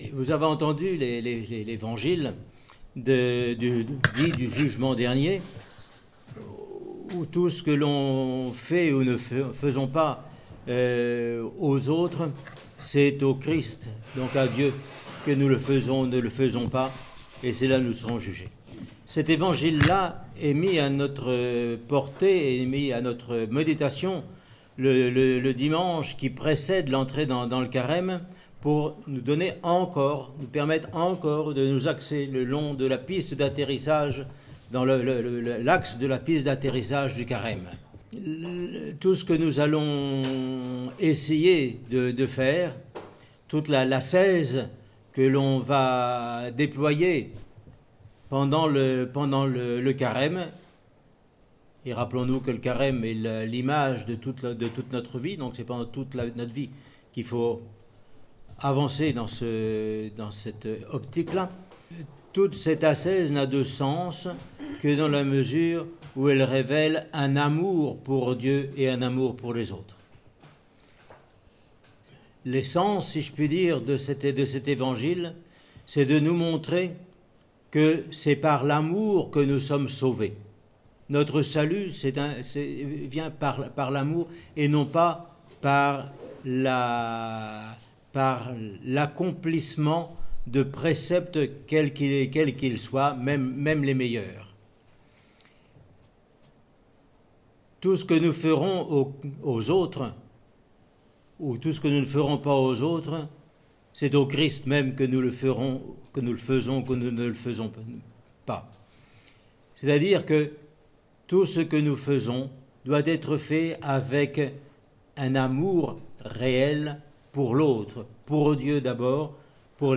Homélie sur le dimanche du jugement dernier :Monastère de la Transfiguration